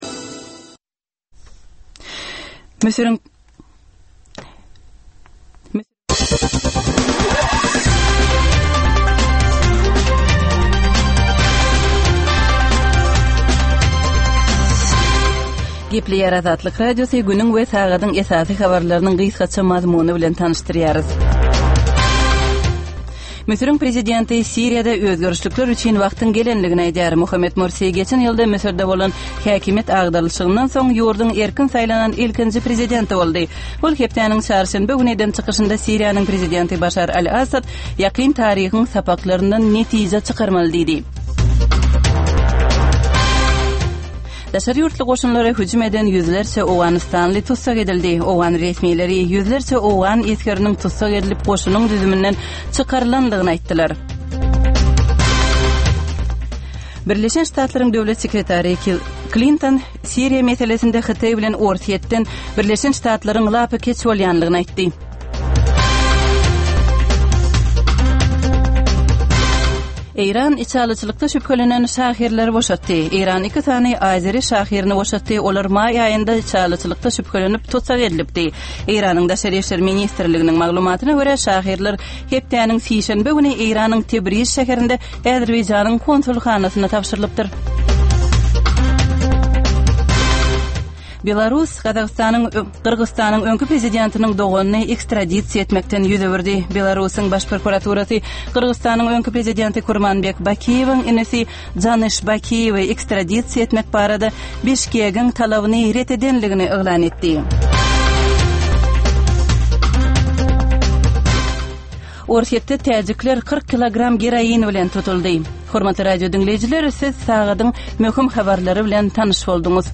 Türkmenistandaky we halkara arenasyndaky möhüm wakalar we meseleler barada ýörite informasion-analitiki programma. Bu programmada soňky möhüm wakalar we meseleler barada analizler, synlar, söhbetdeşlikler, kommentariýalar we diskussiýalar berilýär.